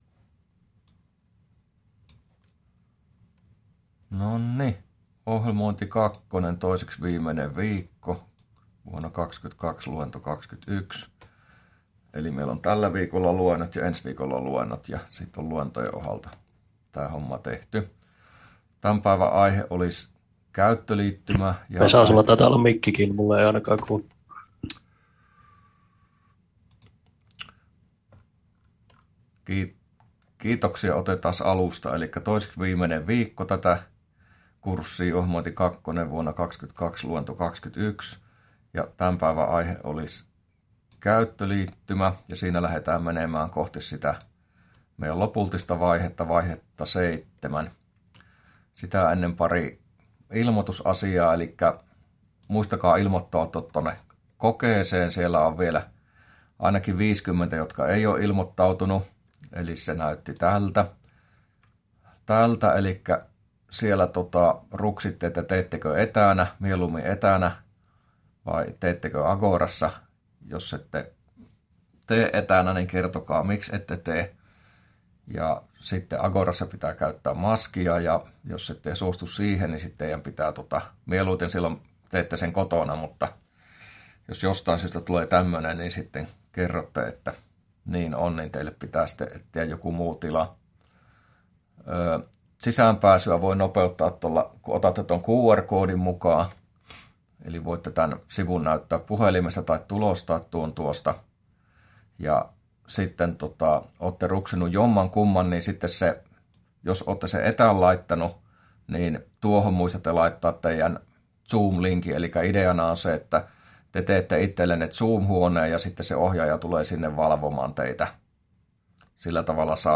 luento21a